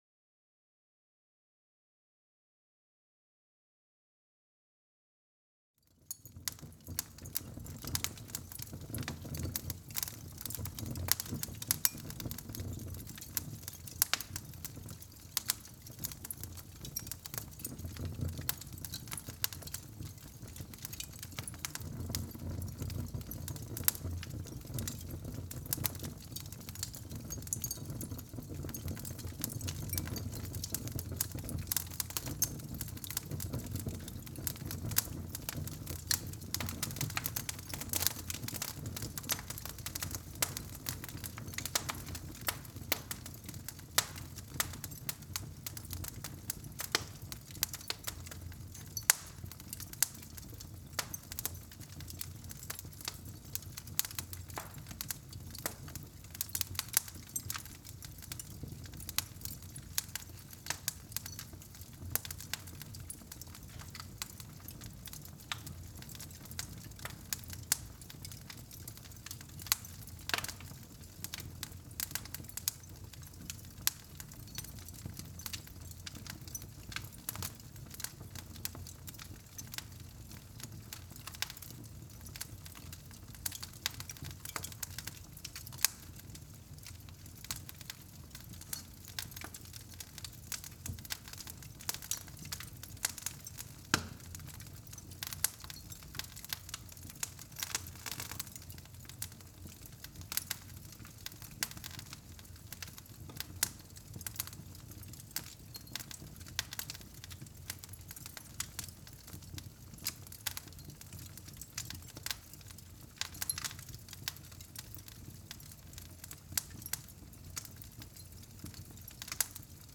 SFX_FireBig.ogg